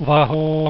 wahoooooo16b.wav